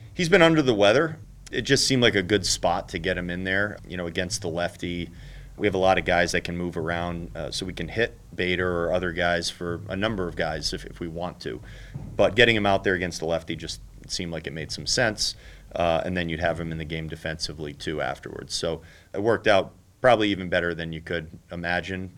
Manager Rocco Baldelli says he used Bader as a pinch hitter in the seventh and it paid off.